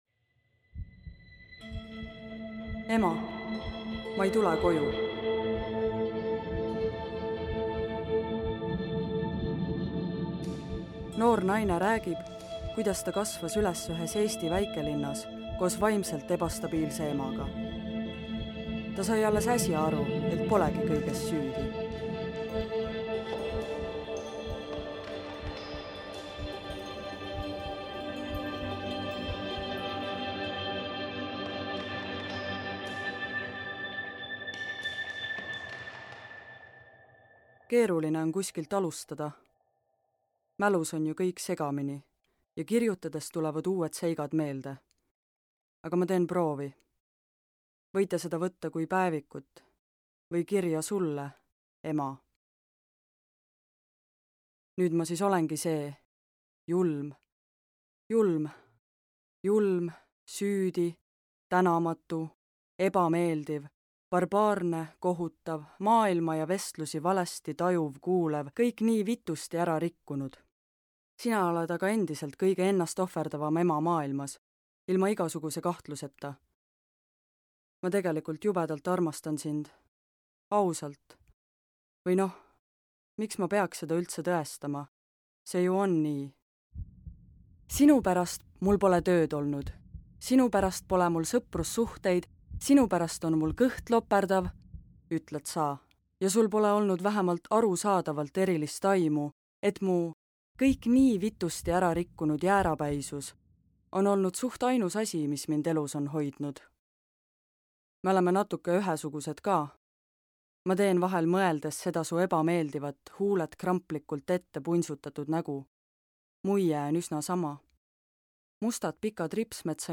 Send us a textNoor naine räägib, kuidas ta kasvas üles ühes Eesti väikelinnas koos vaimselt ebastabiilse emaga. Ta sai alles äsja aru, et polegi kõiges süüdi.